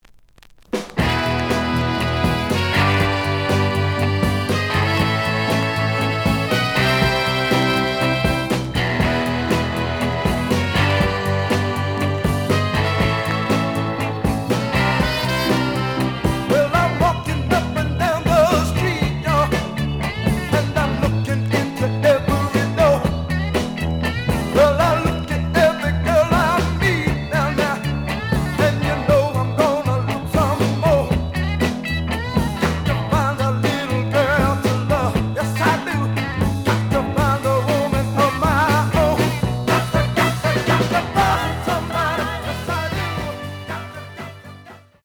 The audio sample is recorded from the actual item.
●Format: 7 inch
●Genre: Soul, 60's Soul
Some click noise on A side due to scratches.